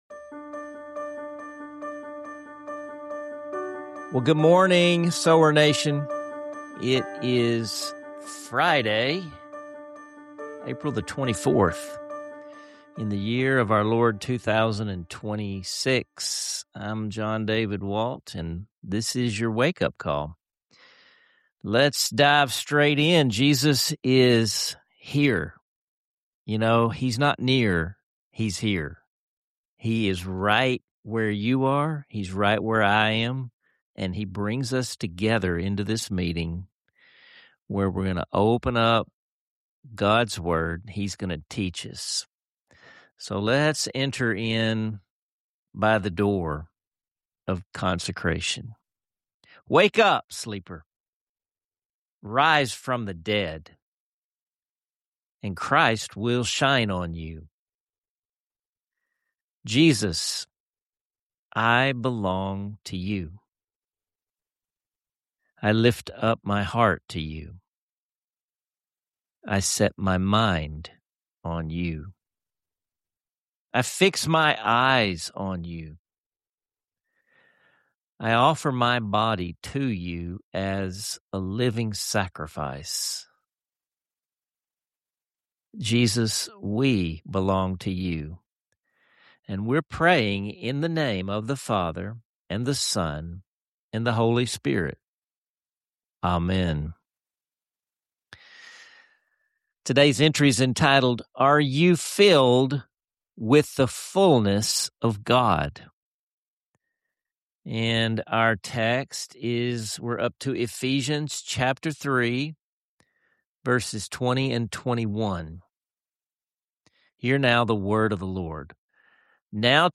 Subscribe for more conversations like this—where scripture, song, and honest questions mingle to stir your spirit!